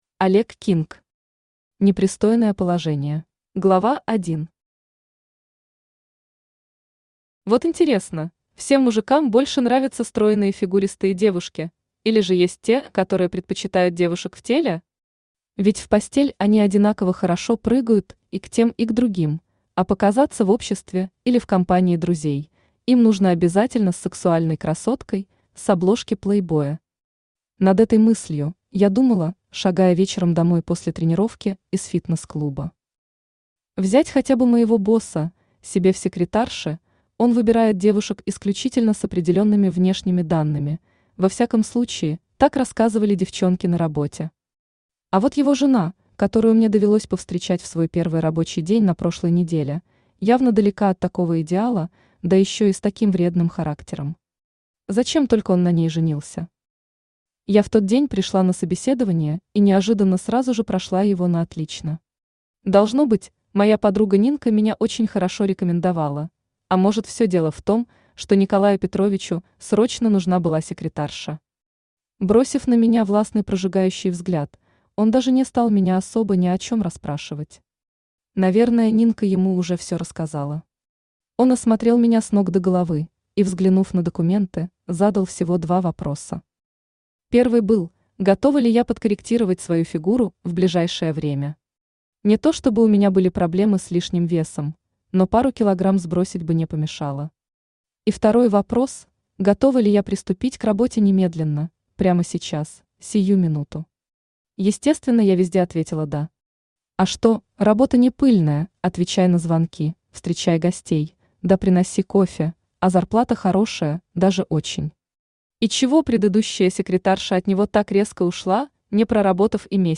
Аудиокнига Непристойное положение | Библиотека аудиокниг
Aудиокнига Непристойное положение Автор Олег Кинг Читает аудиокнигу Авточтец ЛитРес.